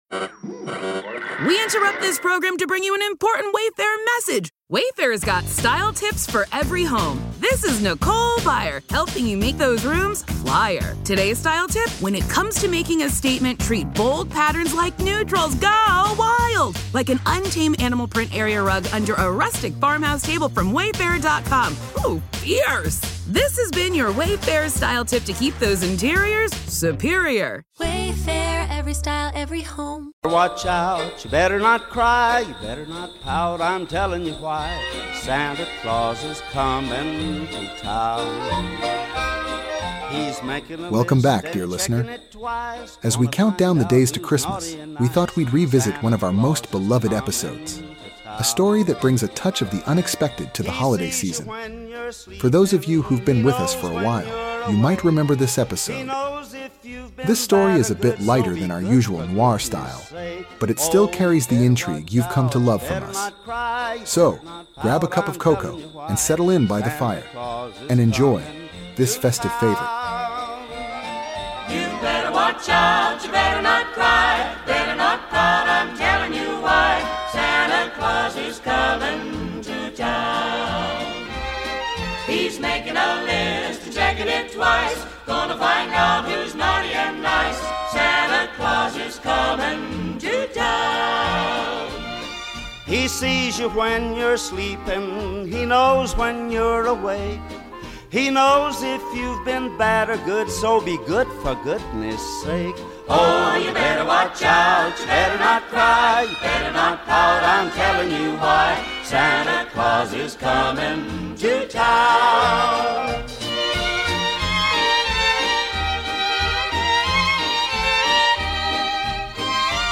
True Crime Podcast